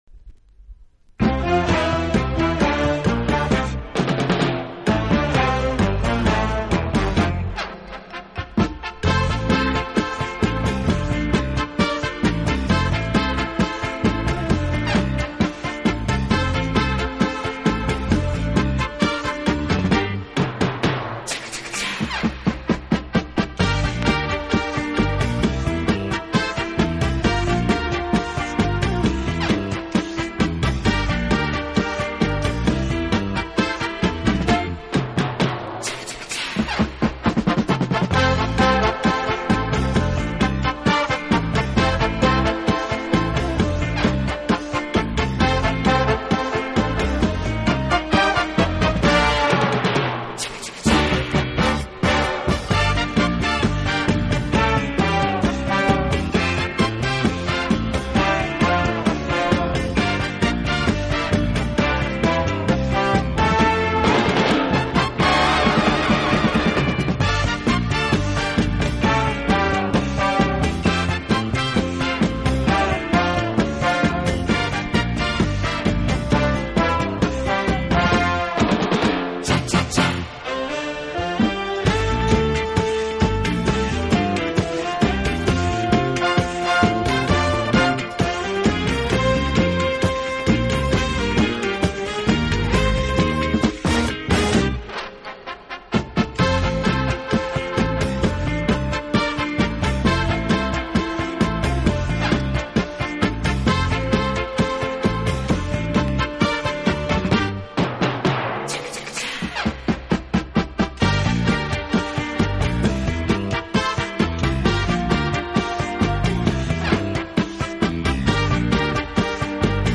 方式，尤其是人声唱颂的背景部分，似乎是屡试不爽的良药。
有动感，更有层次感；既有激情，更有浪漫。
(Cha-Cha)